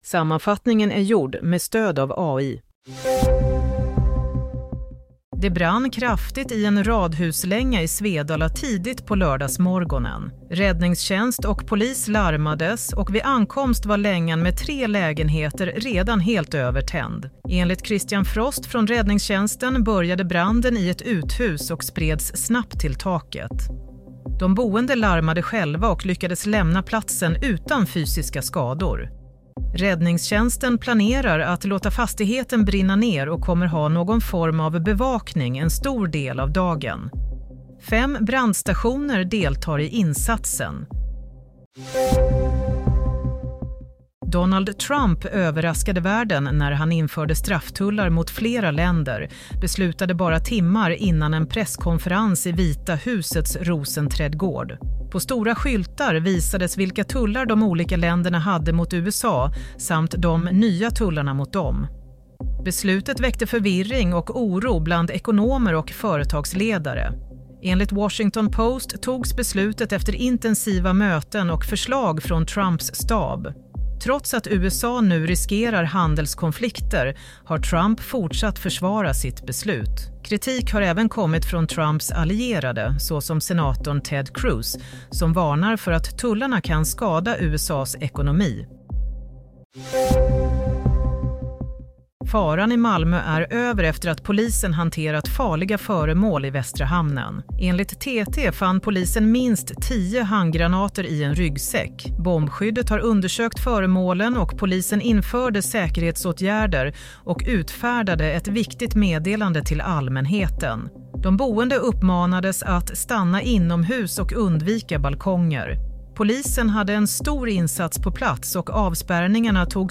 Nyhetssammanfattning - 5 april 07:00
Sammanfattningen av följande nyheter är gjord med stöd av AI.